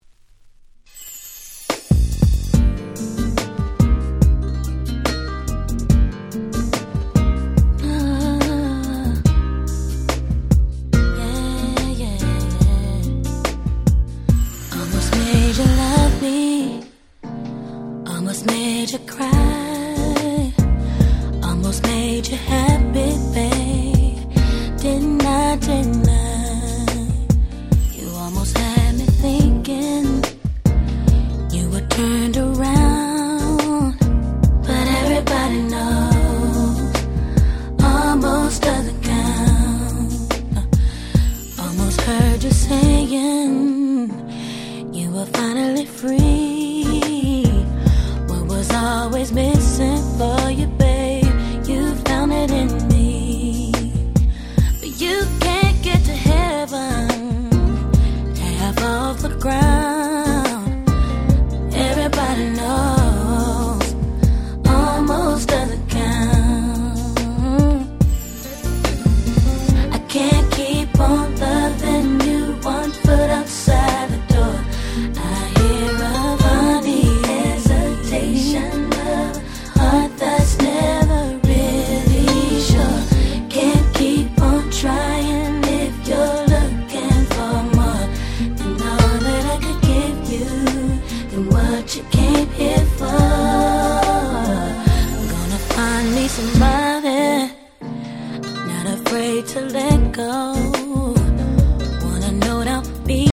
99' Super Hit R&B !!